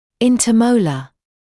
[ˌɪntə’məulə][ˌинтэ’моулэ]межмолярный